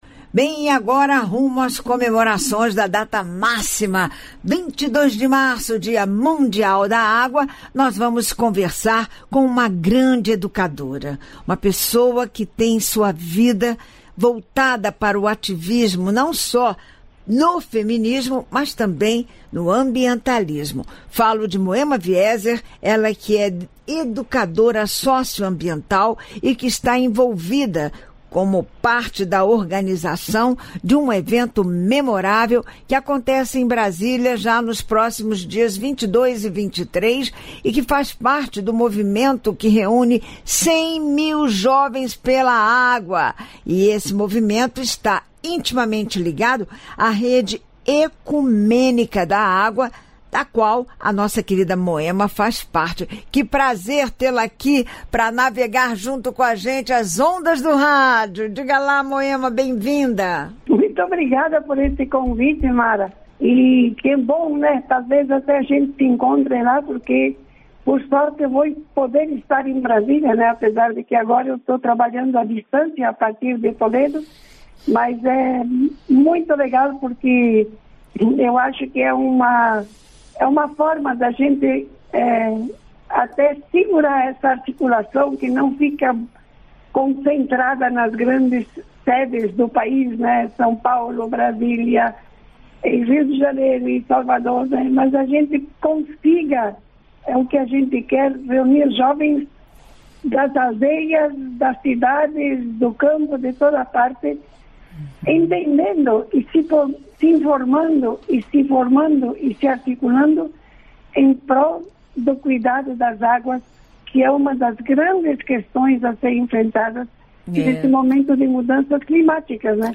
Partilhamos a seguir alguns trechos da sua fala e deixamos no final desta nota o áudio completo da entrevista.